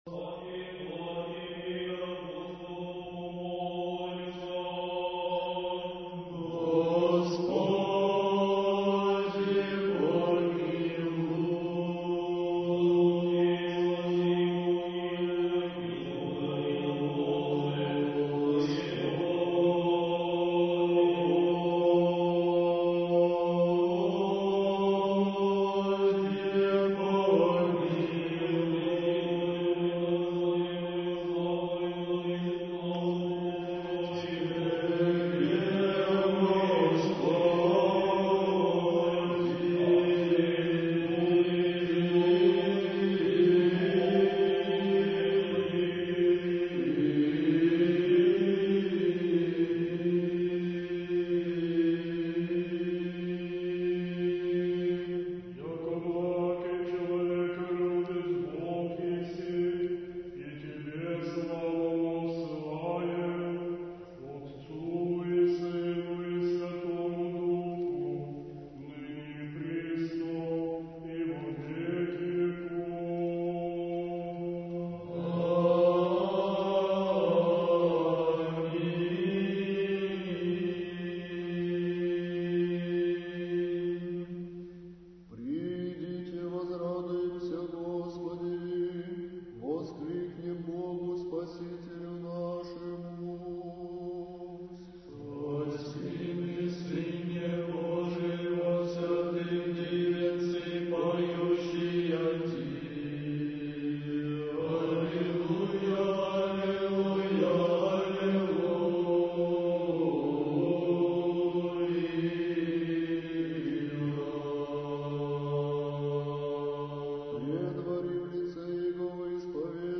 Духовная музыка